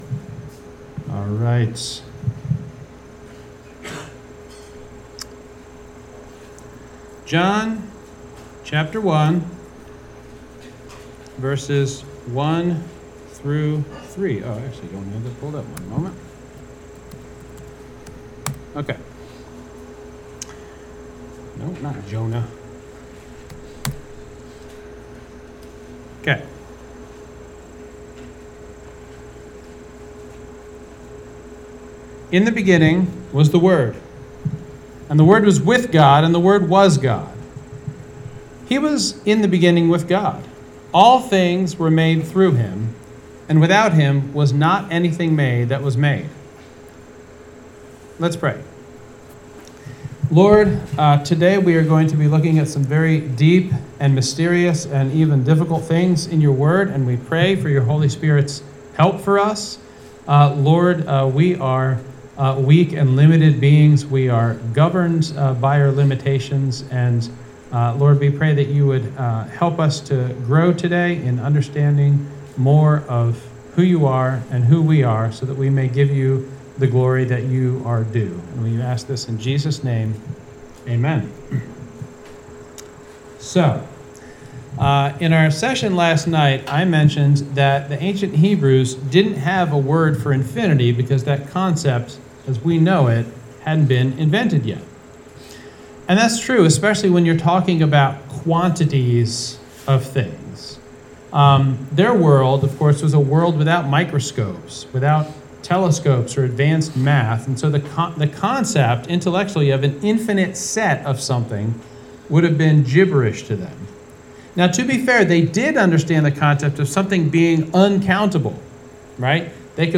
2025 Stronghold Sermon Series #2: Eternity